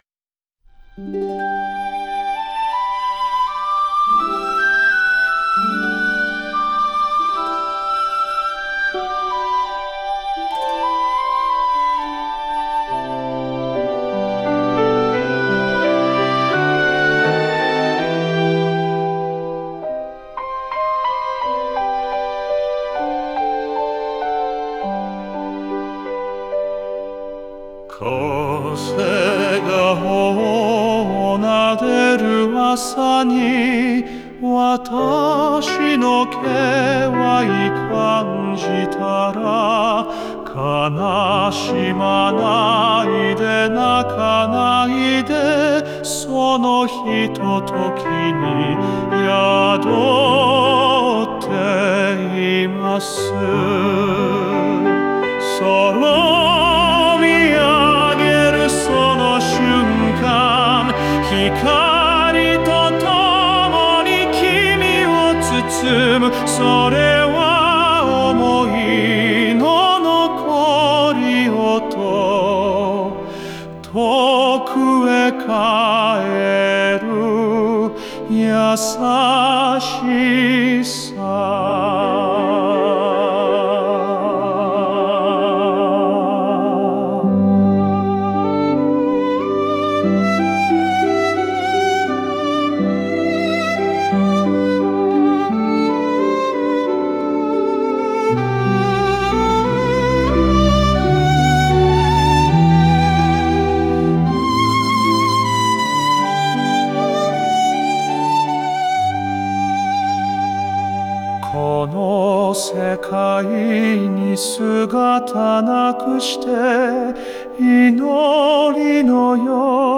聴く人の心に深く届く鎮魂歌となる構成です。